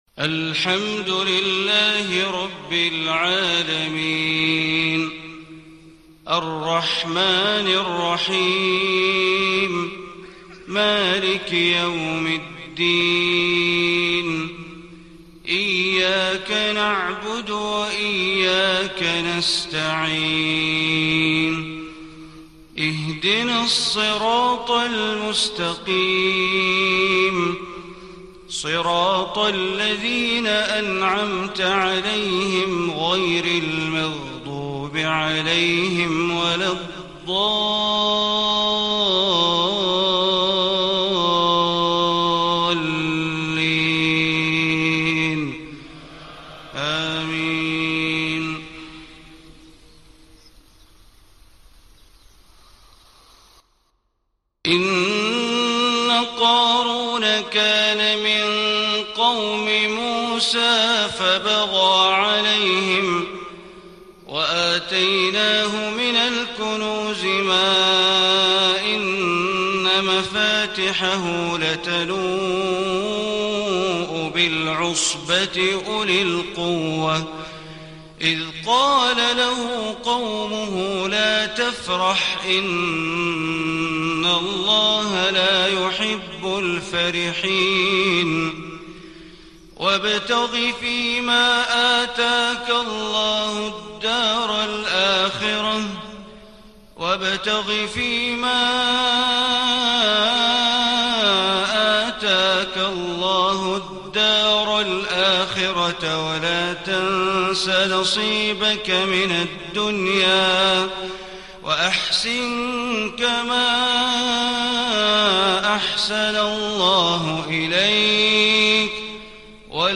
صلاة الفجر 1-4-1437هـ خواتيم سورة القصص 76-88 > 1437 🕋 > الفروض - تلاوات الحرمين